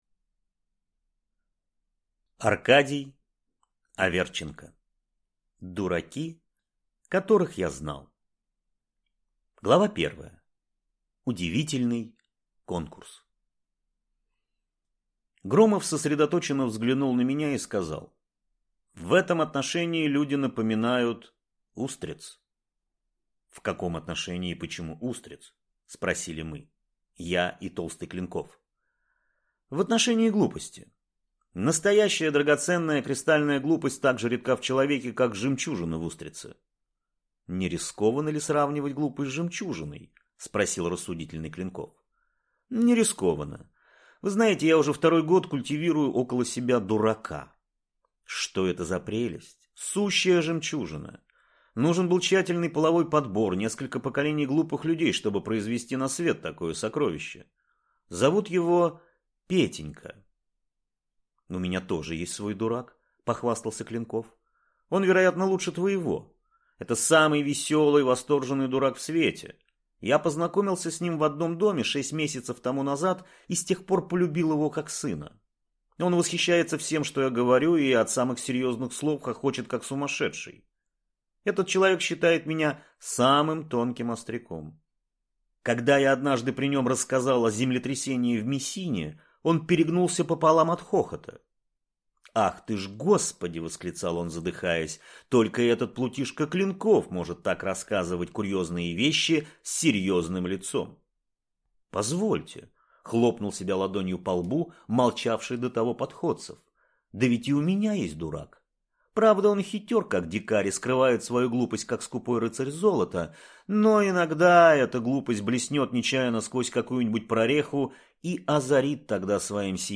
Аудиокнига Дураки, которых я знал | Библиотека аудиокниг